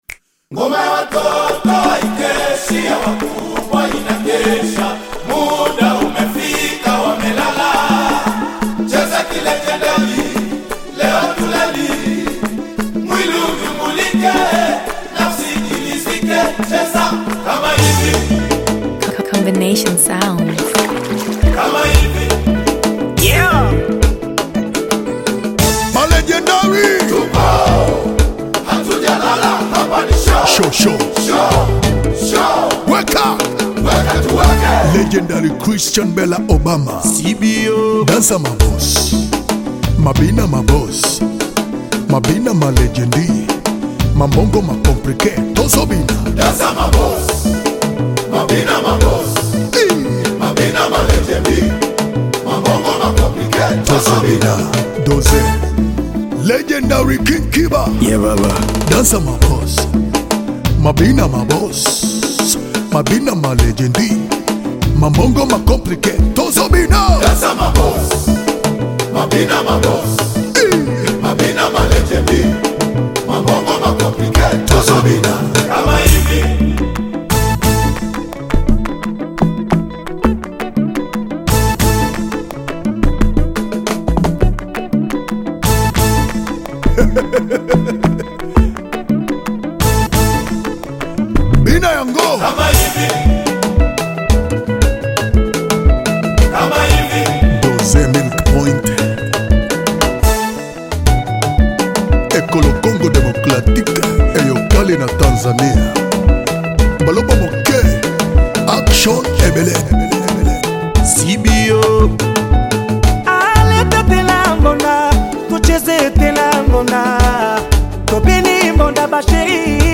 Bongo Flava You may also like